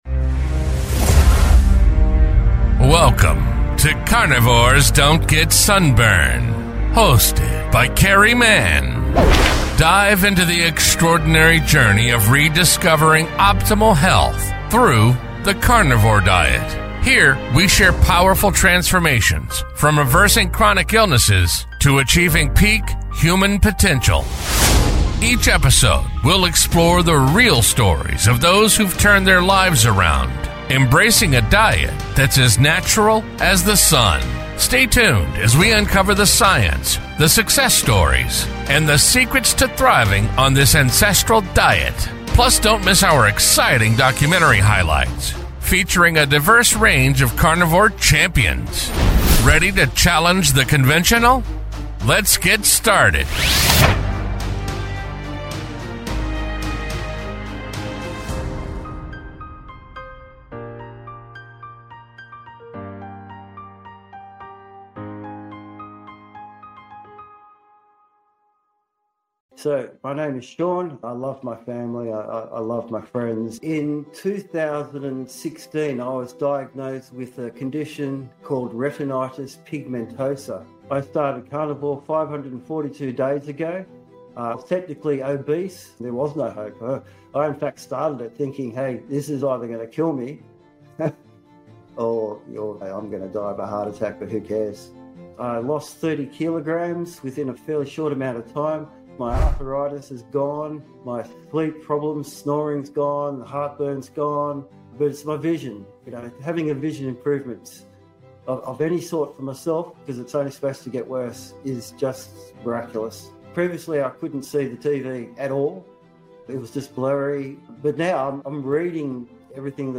In this episode, I interview